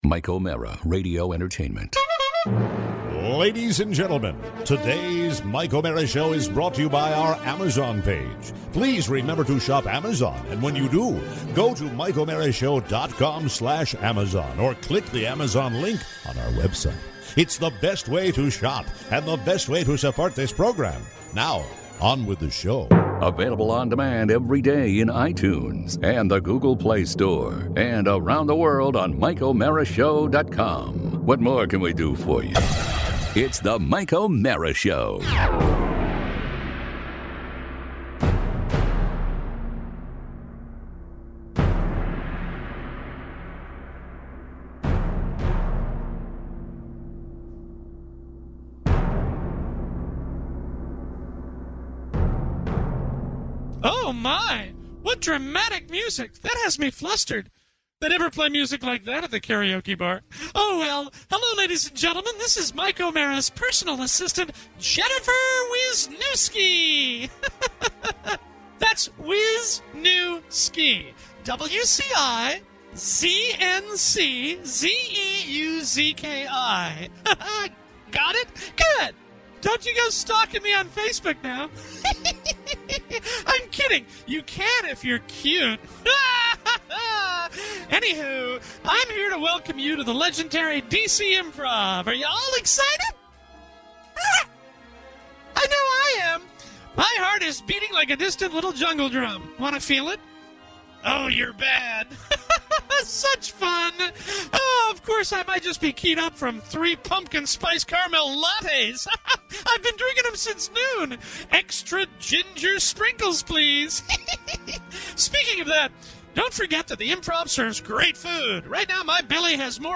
#1641: Live At The DC Improv
It’s our November 5th live show at The Washington DC Improv!